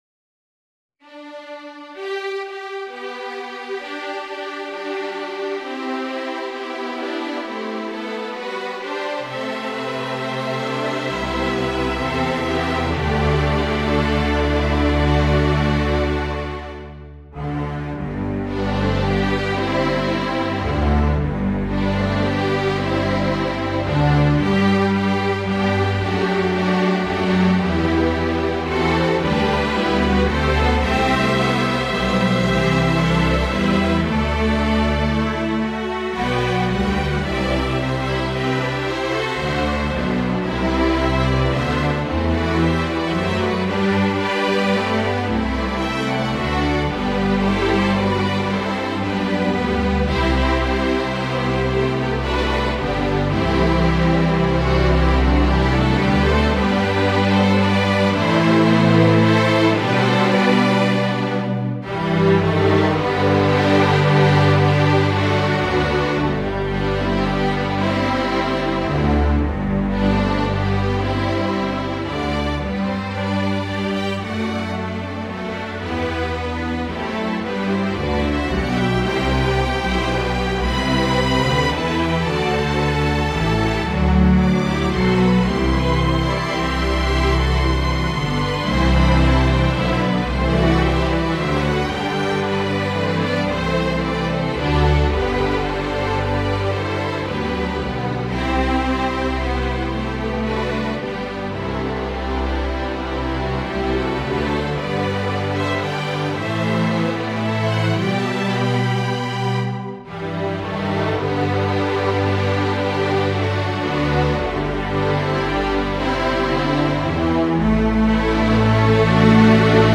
A lovely lilting arrangement
in 6/8 time for String Orchestra
Folk and World